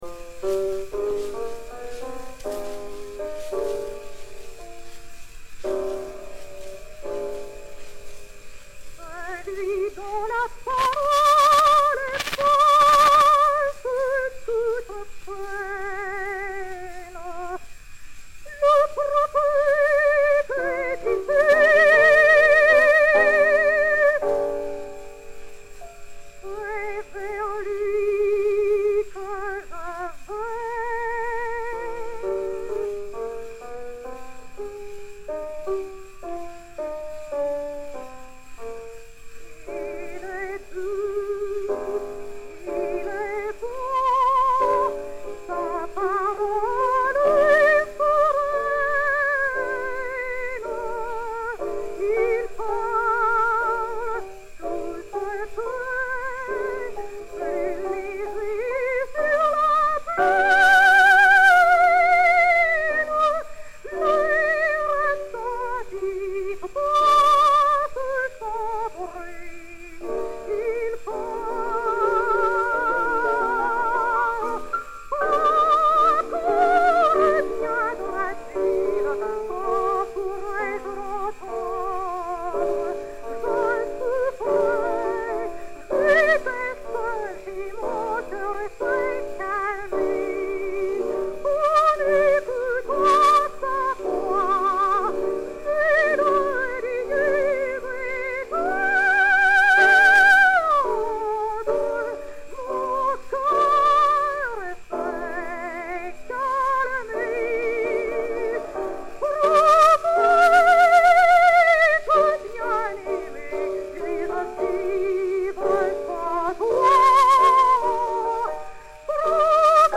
Air "Il est doux, il est bon"
Rose Heilbronner (Salomé) et Piano